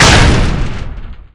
door_stop.ogg